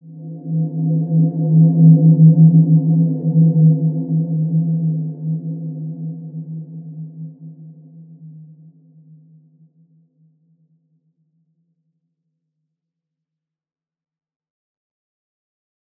Wide-Dimension-E2-mf.wav